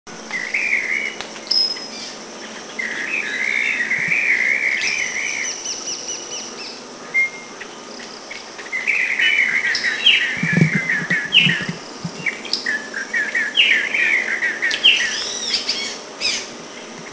Coleto.mp3